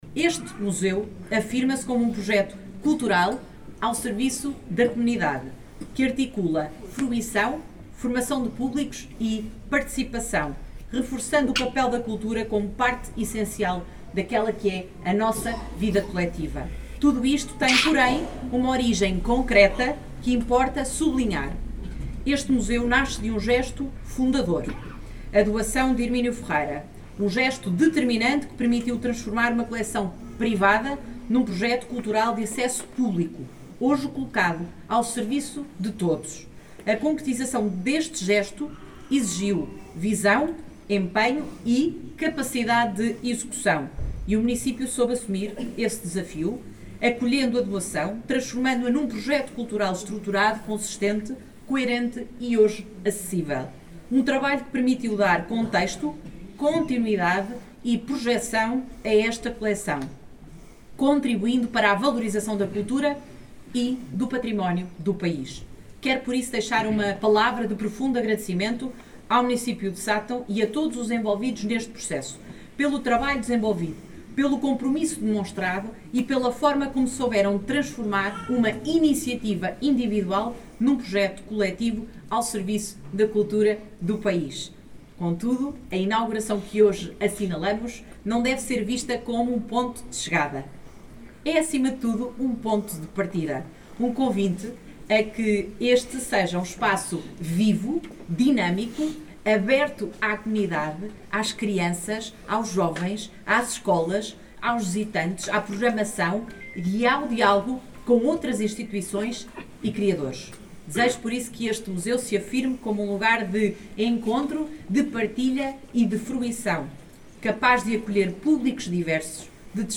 Nesta segunda-feira, 19 de janeiro, a localidade de Pedrosas, no concelho de Sátão, foi palco da inauguração do Museu de Pintura Naïf, numa cerimónia que contou com a presença da Ministra da Cultura, Juventude e Desporto, Margarida Balseiro Lopes, e de D. António Luciano, Bispo de Viseu.
A Ministra da Cultura, Juventude e Desporto, Margarida Balseiro Lopes, deixou uma mensagem sobre a importância desta nova valência cultural para a região, sublinhando o papel do Museu de Pintura Naïf na valorização do património e na promoção do desenvolvimento local.
M.Cultura-Margarida-Balseiro-Lopes.mp3